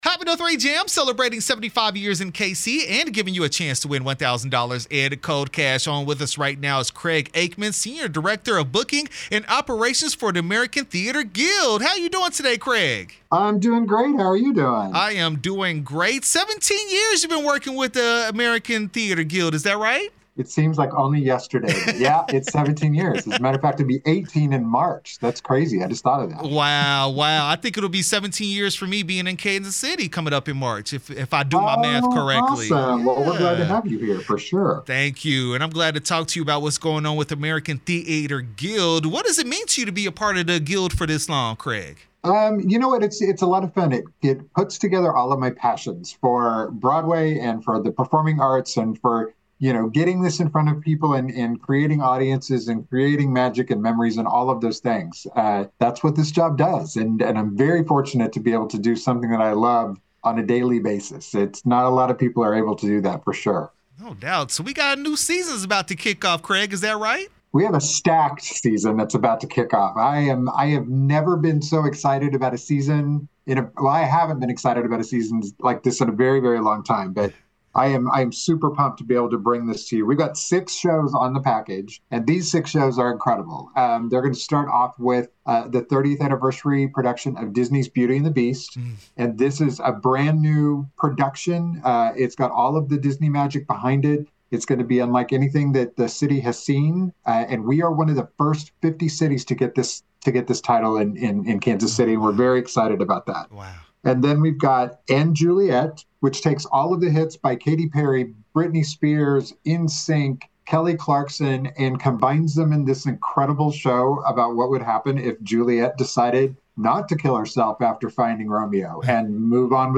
American Theatre Guild interview 2/19/25